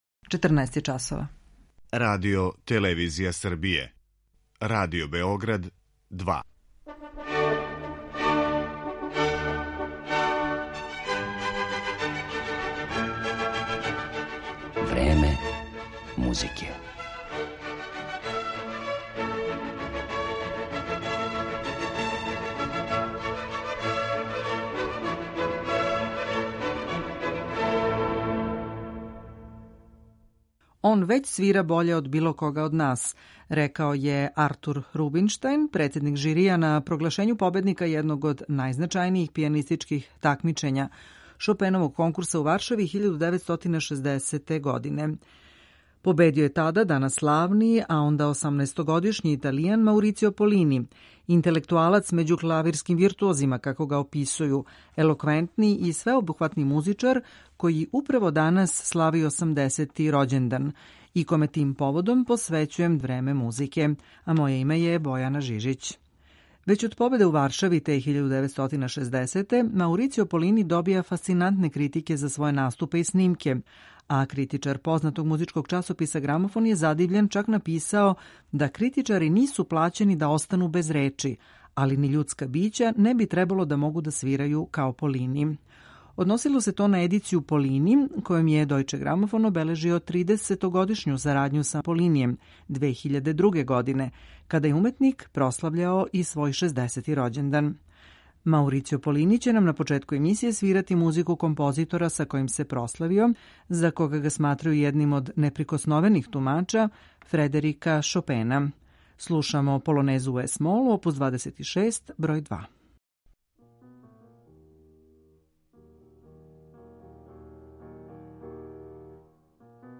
Овај славни италијански солиста изводиће композиције Фредерика Шопена, Јоханеса Брамса, Игора Стравинског, Лудвига ван Бетовена и Роберта Шумана.